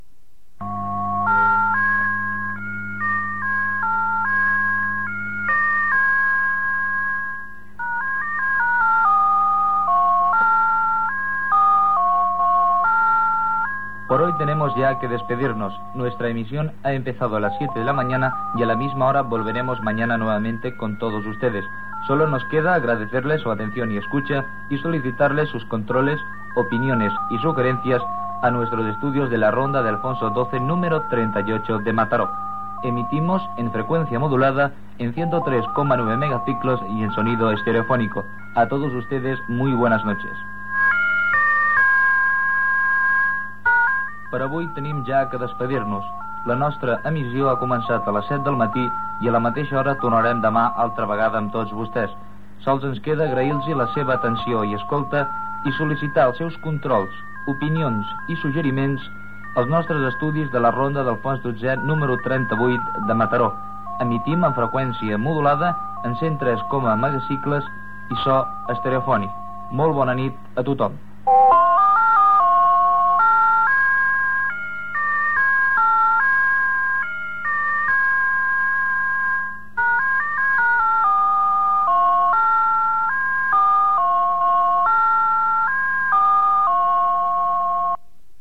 Tancament d'emissió i sintonia de l'emissora.